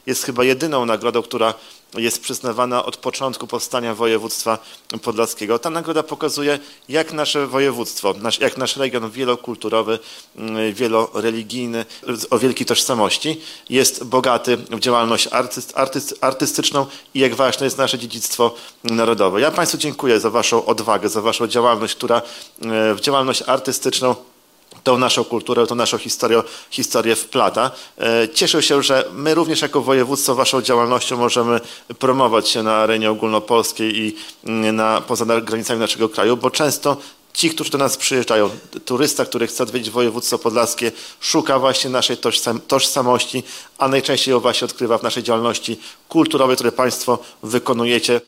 Nagroda w dziedzinie kultury przyznawana jest od 26 lat. Mówi marszałek Łukasz Prokorym.